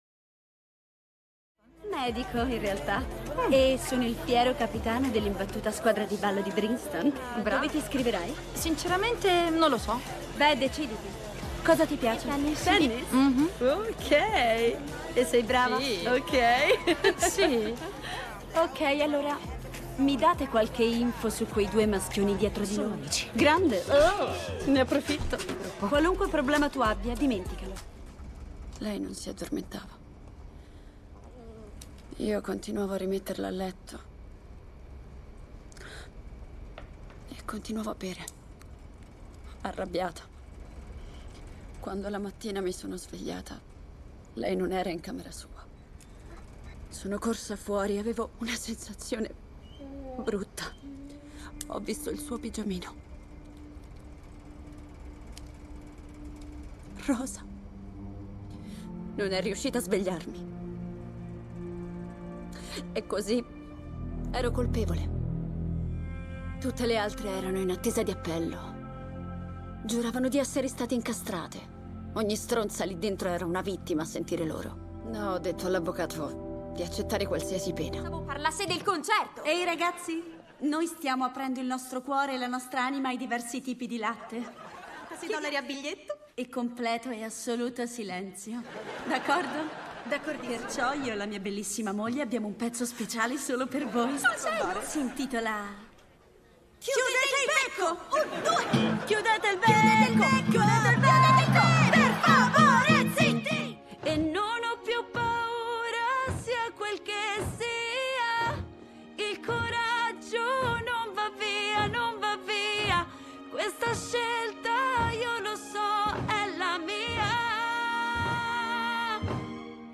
FILM CINEMA
TELEFILM